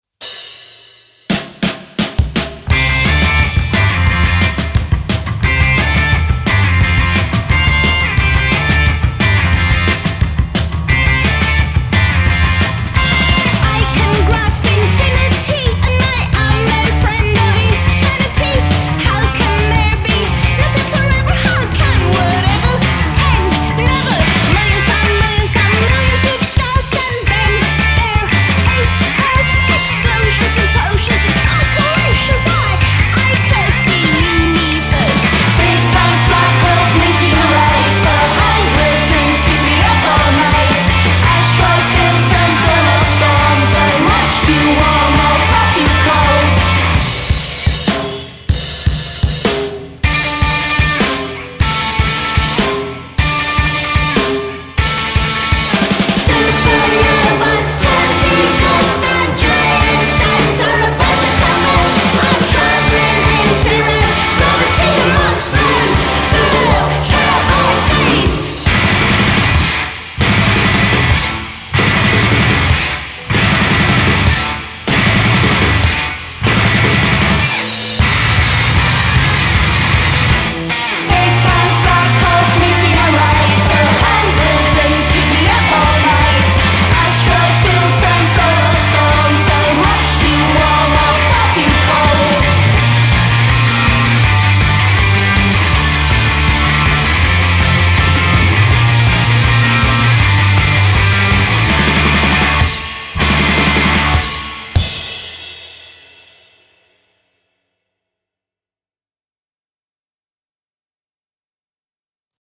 nervosa e ossessiva, puro ghiaccio secco